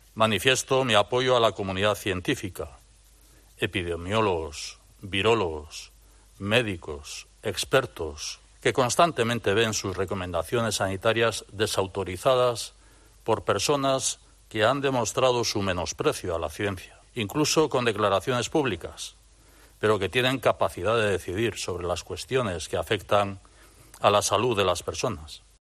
Iñigo Urkullu, lehendakari